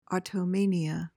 PRONUNCIATION:
(aw-toh-MAY-nee-uh)